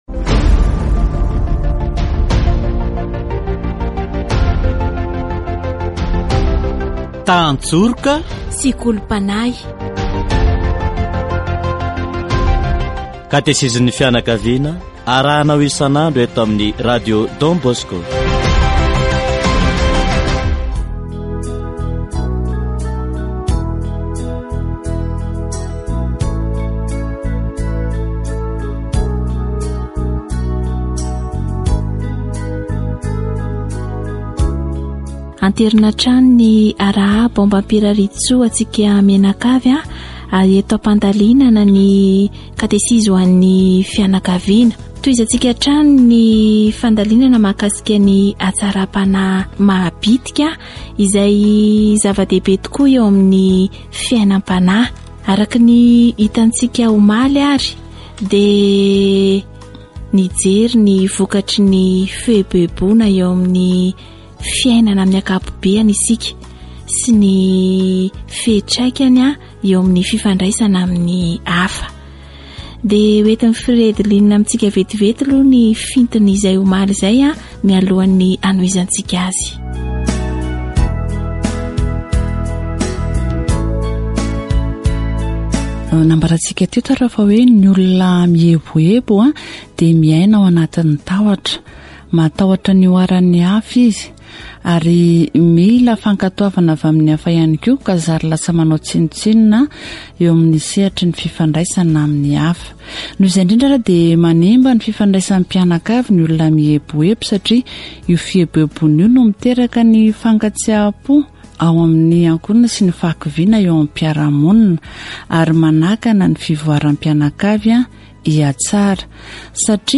Catégorie : Approfondissement de la foi